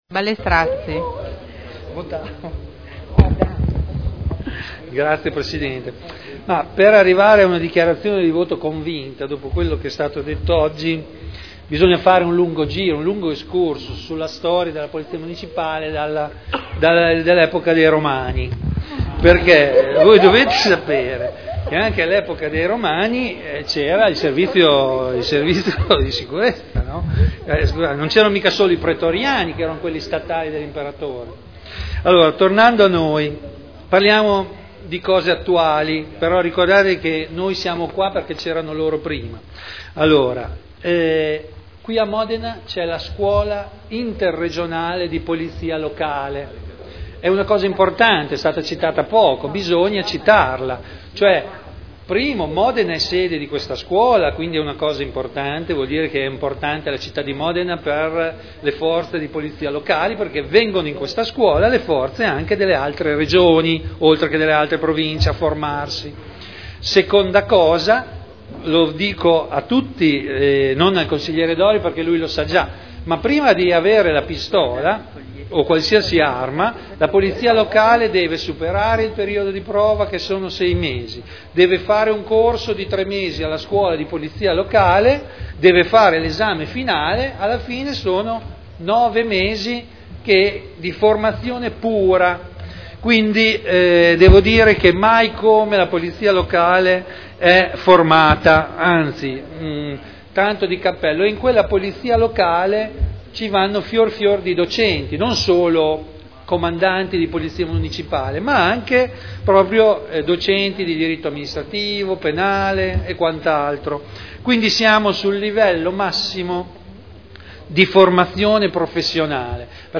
Seduta del 05/12/2011. Dichiarazione di voto.